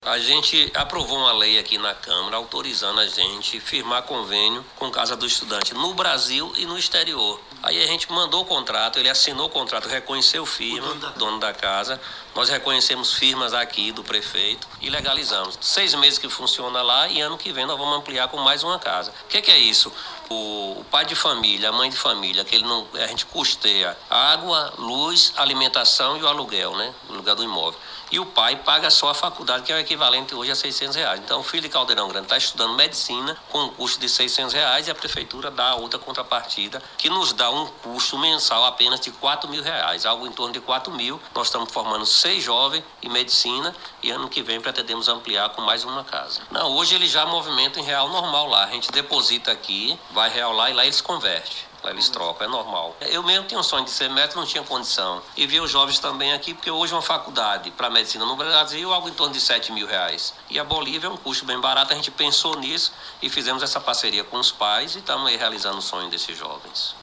PREFEITO-CALDEIRAO-GRANDE-1.mp3